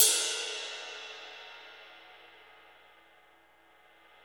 CYM XRIDE 5D.wav